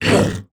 damage_2.wav